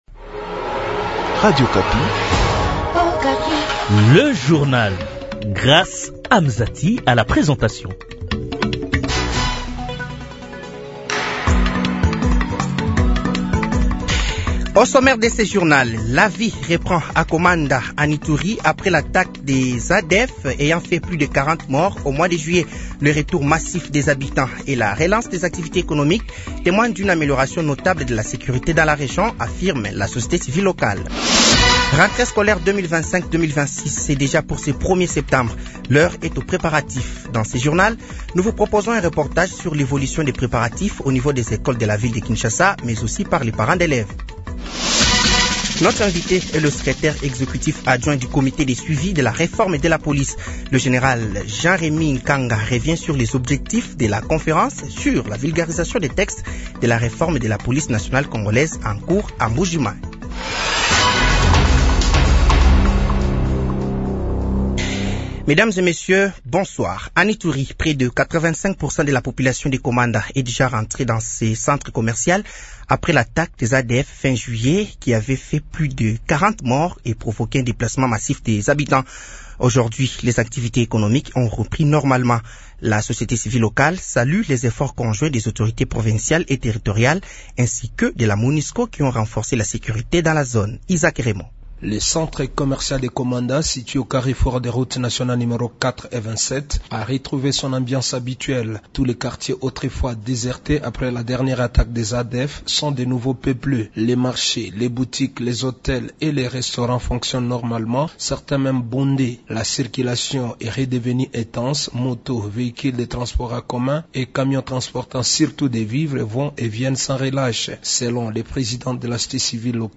Journal francais de 18h de ce jeudi 21 août 2025